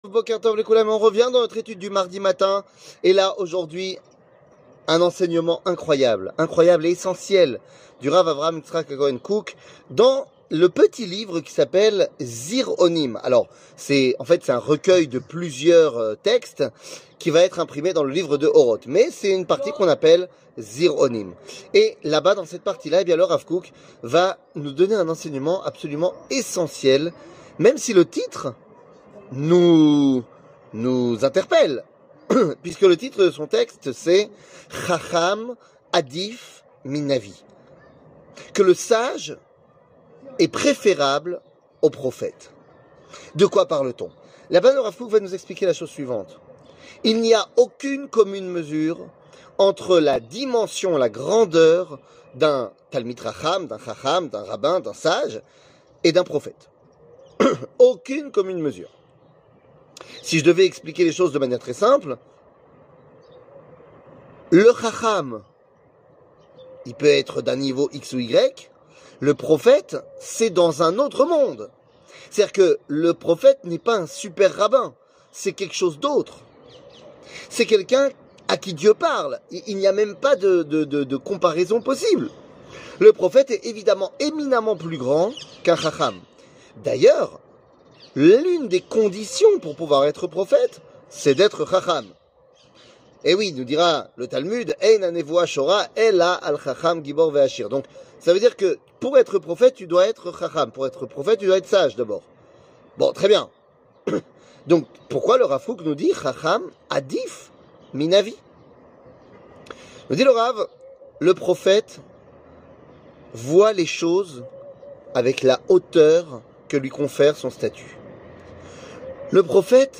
שיעור מ 30 מאי 2023